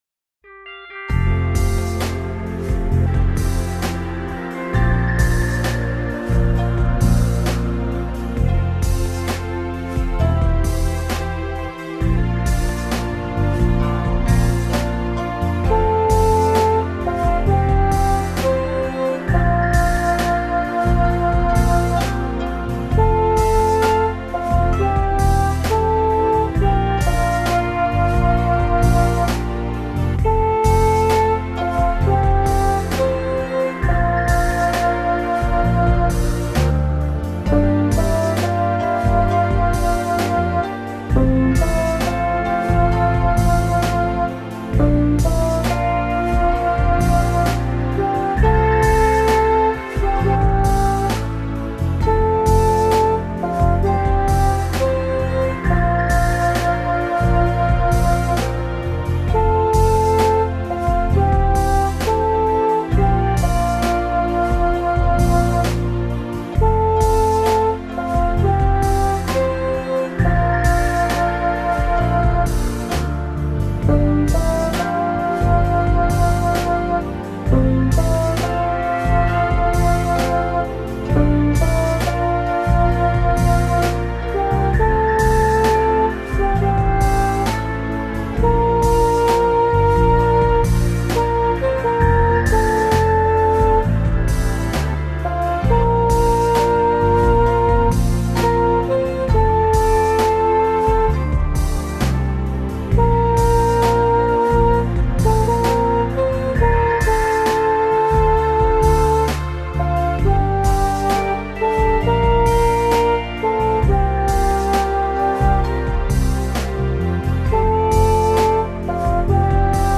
chant
My backing uses a BIAB style with loops: